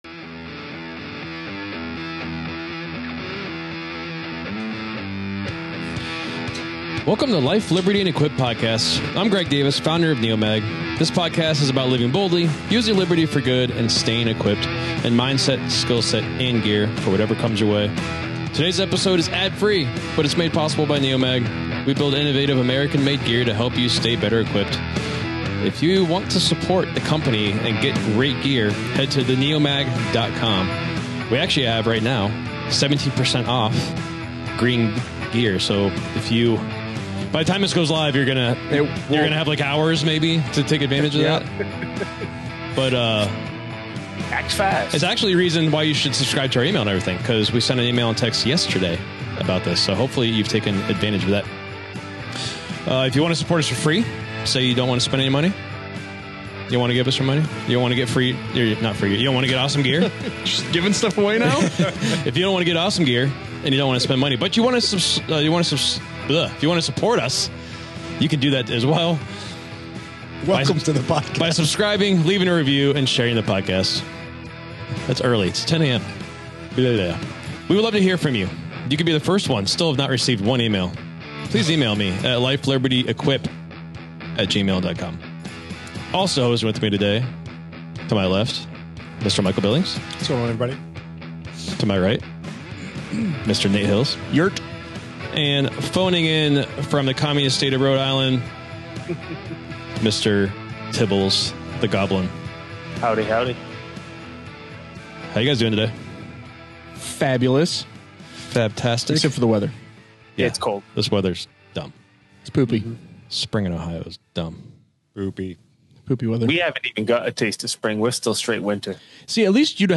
Each question is multiple choice, answers are locked in individually, and the banter, trash talk, and second-guessing come fast.